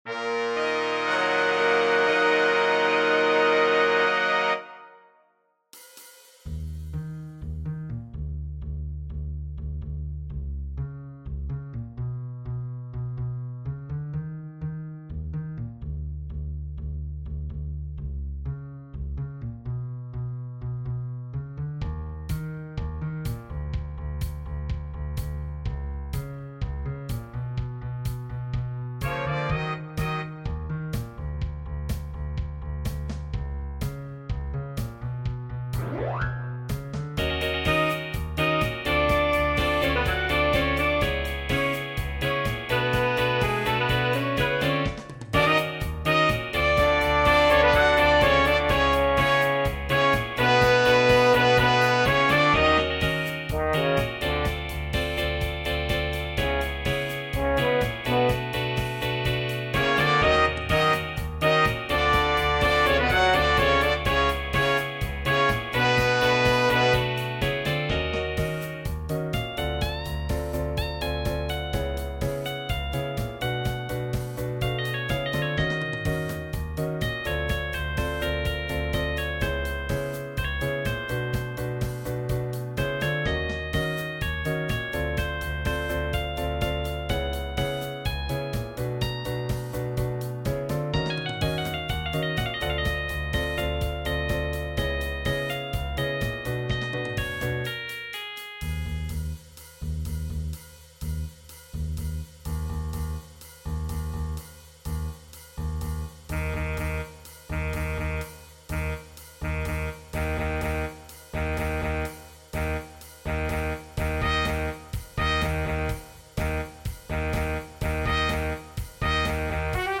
For Jazz Ensemble
You start off in a dramatic fashion when you first meet the one you love.
Being a Neo-Funk chart the rhythm section needs to experiment with funky sounds and heavy groove.
This is a funky love story.